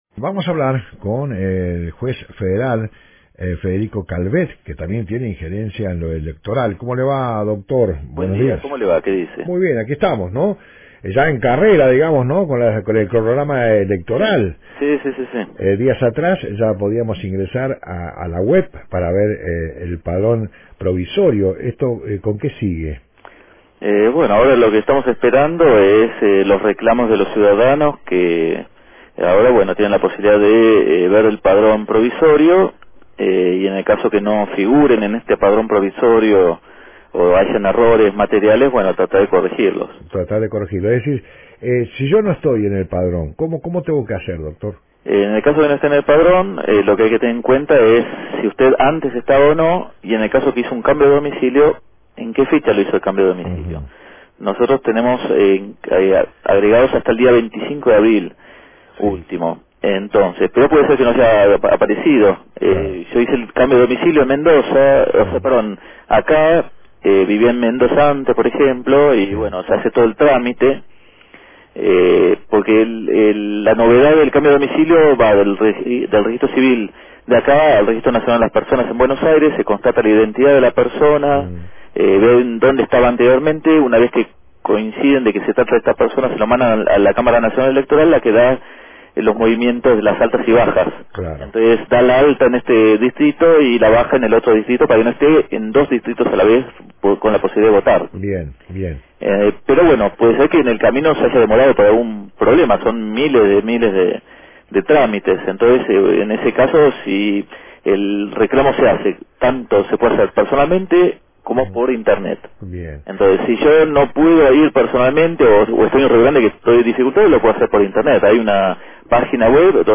Esta mañana, el Dr. Federico Calvete, juez Federal en las dos jurisdicciones de la provincia y por ende a cargo de la Justicia Electoral, dialogó en exclusiva con Radio Fueguina respecto a los comicios que se avecinan y brindó recomendaciones para que los vecinos no tengan problemas a la hora de sufragar.